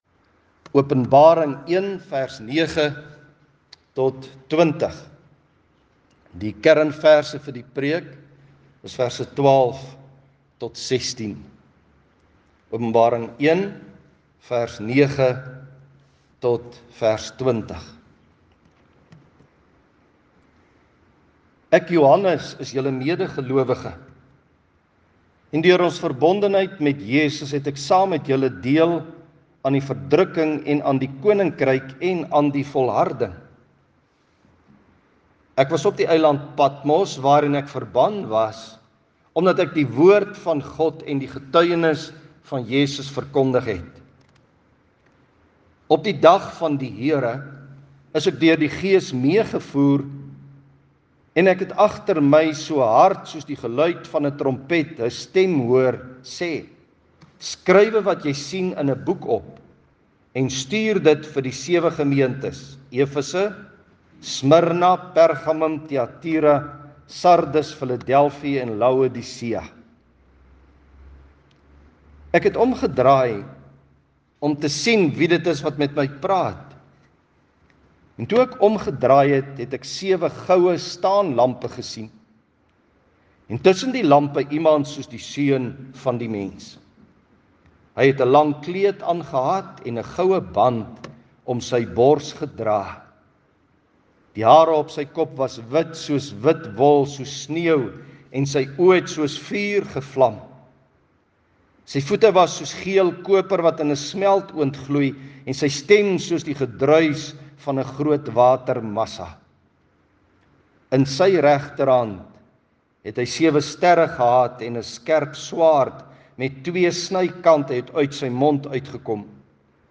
Klankbaan